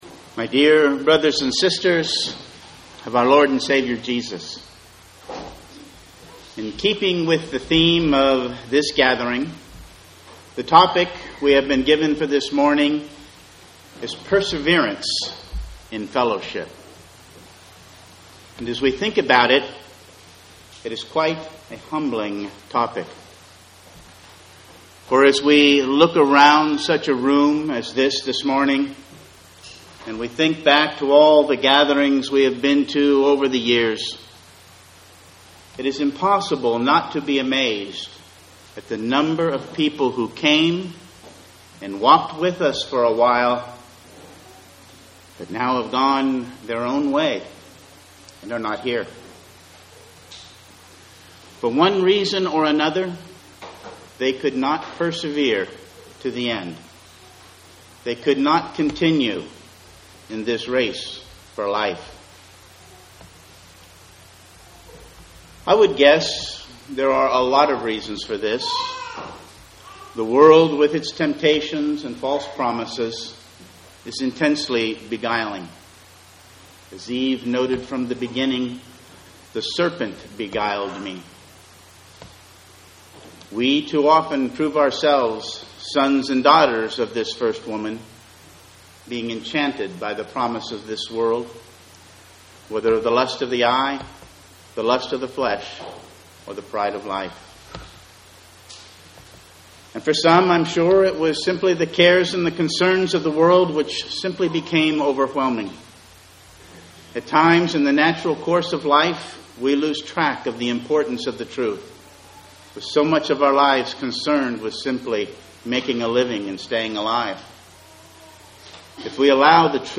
The recordings of the talks from the 2015 Houston Gathering are now available for downloading.